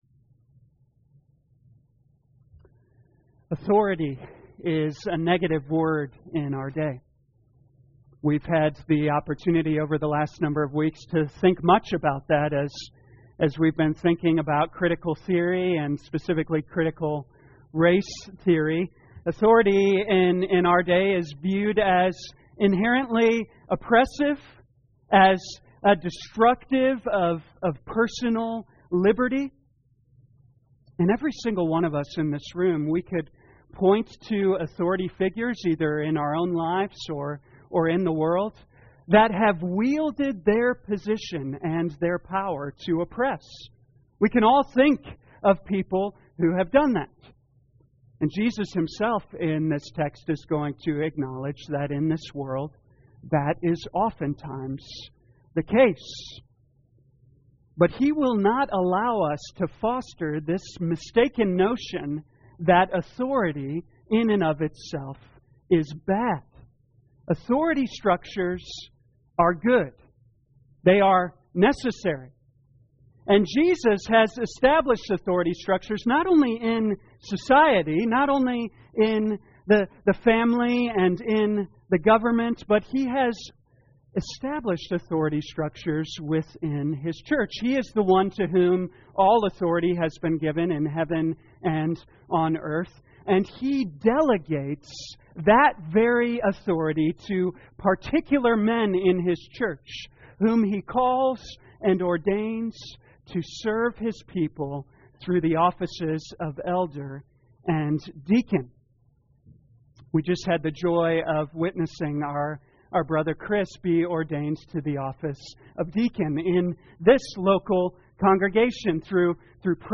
2021 Mark Morning Service Download